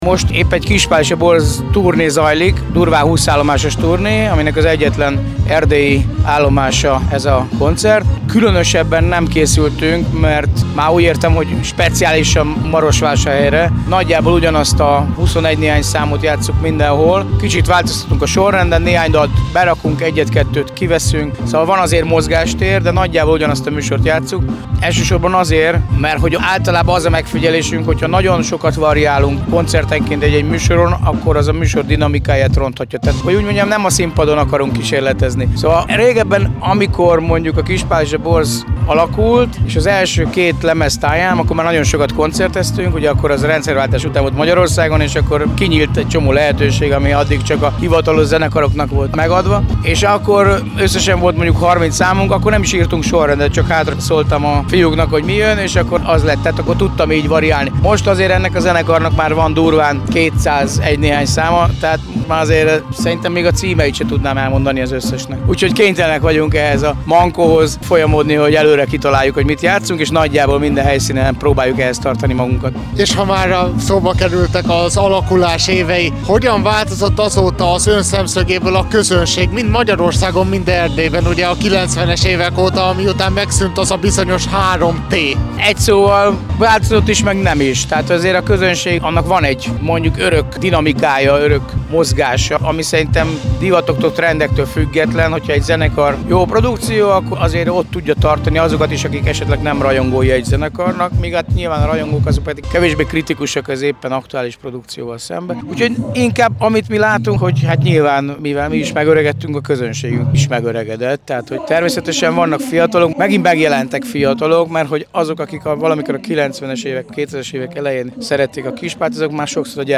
Soós Zoltán hallják!
A közönségről, a tervekről és a jövőbeli fellépésekről beszélgettünk Lovasi Andrással, a Kispál és a borz frontemberével, a színpadra lépésük előtt!